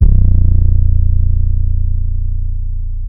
808 (FWM).wav